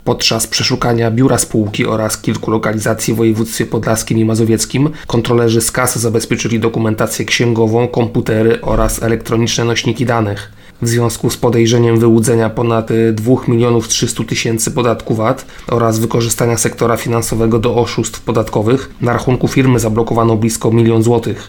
mówi starszy aspirant